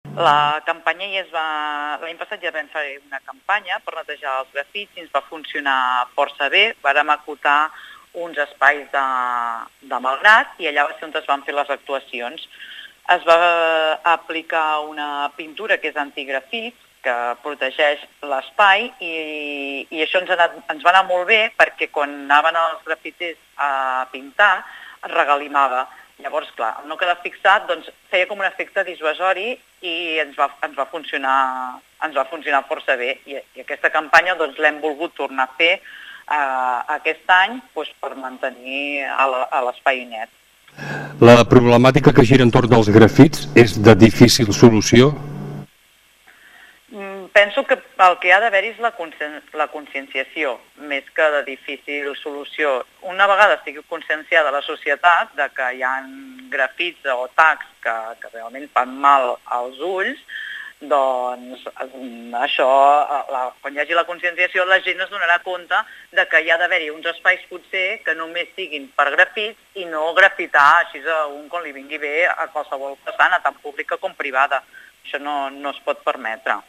Carme Ponsa és l’alcaldessa de Malgrat de Mar.